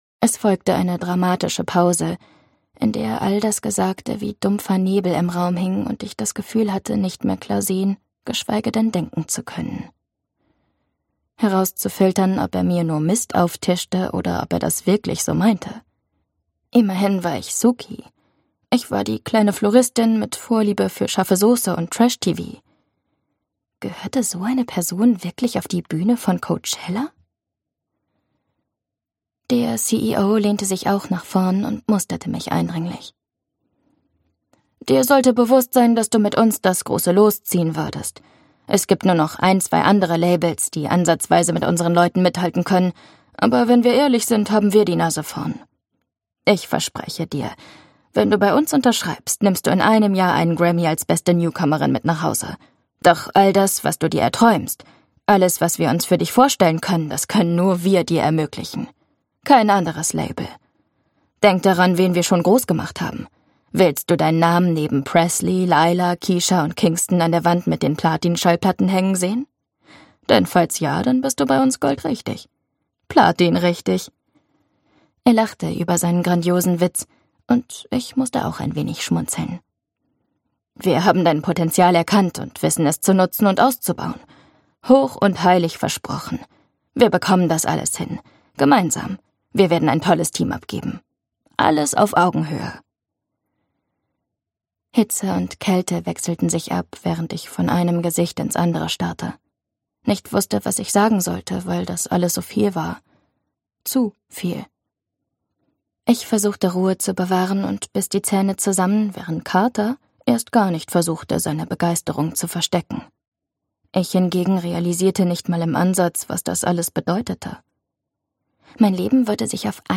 Ausgabe: Ungekürzte Lesung, Hörbuch Download